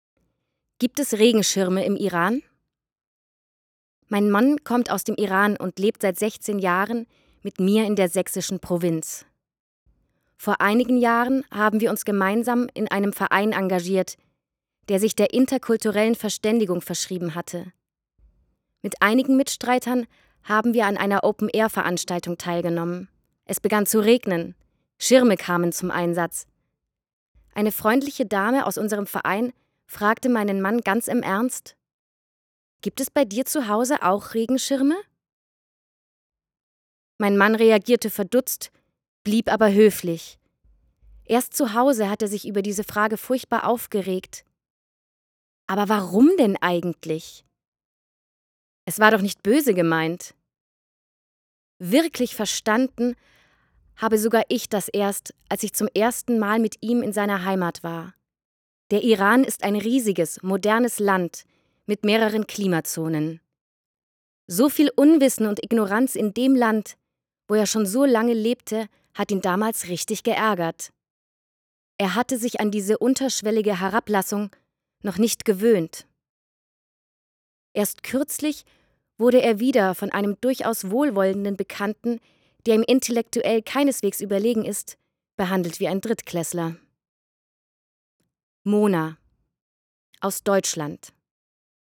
Aus diesem Anlass haben wir Geschichten von Menschen zusammengetragen, die von Alltagsrassismus betroffen sind. Drei Schauspielerinnen des Theaters Plauen-Zwickau haben diesen Menschen eine Stimme gegeben.